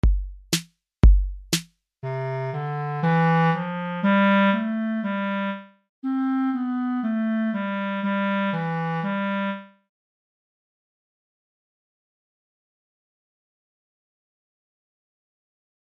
Deseguido atoparedes as diferentes melodías para imitar empregando os vosos instrumentos.